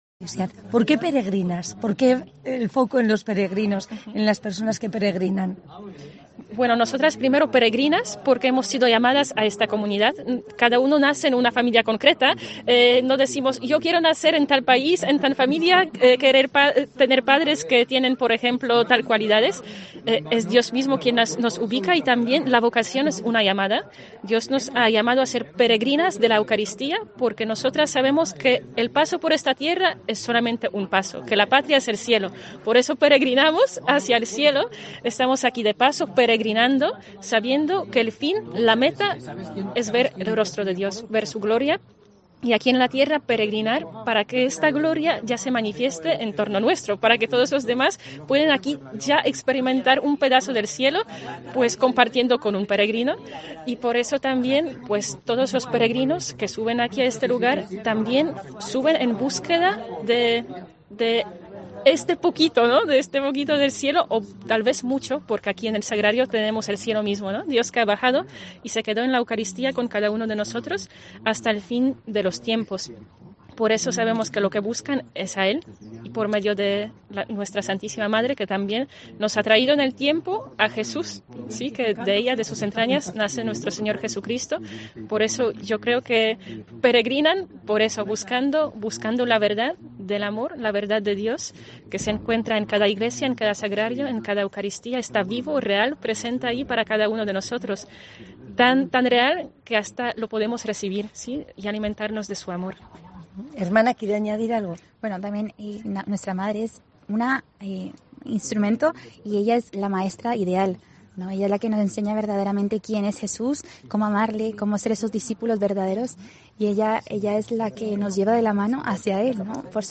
Celebración en Estíbaliz: las hermanas peregrinas con los periodistas y comunicadores